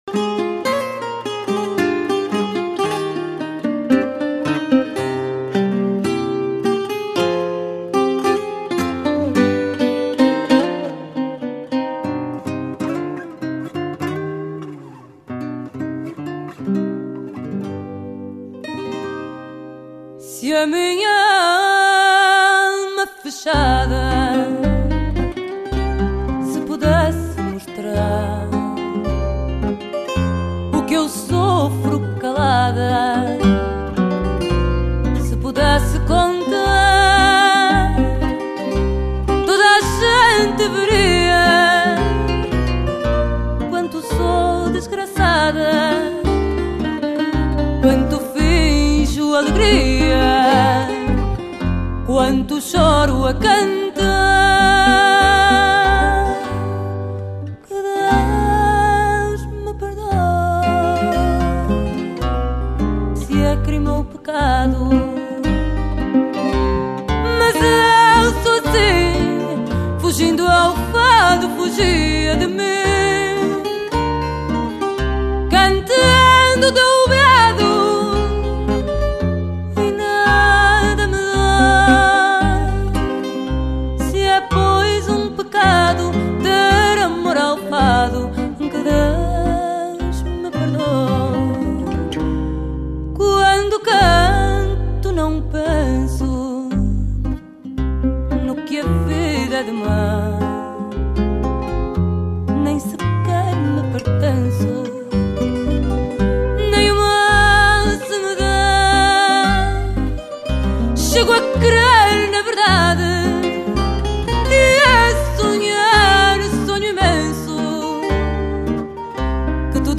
Фаду в исполнении Маризы